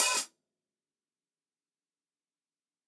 TUNA_OPEN HH_1.wav